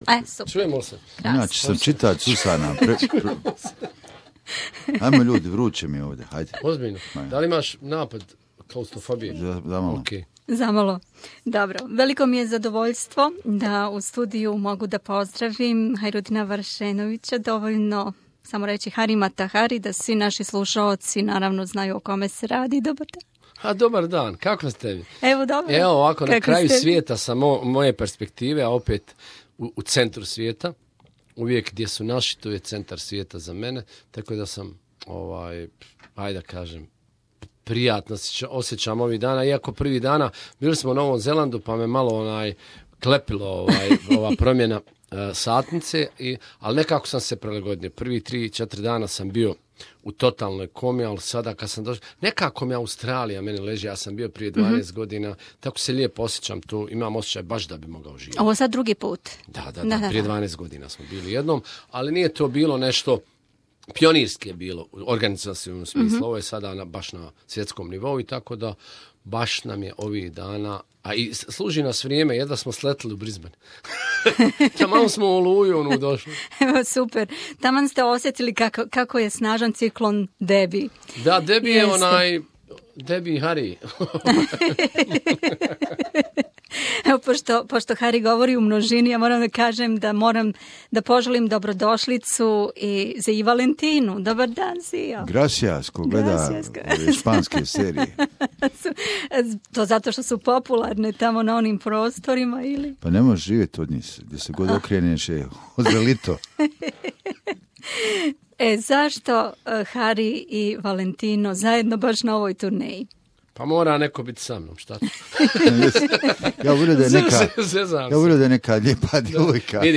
Да сазнате, слушајте овај врло опуштен и духовит разговор са два фантастична босанска музичара који су посетили наш студио овог поподнева, а после концерта у Мелбурну.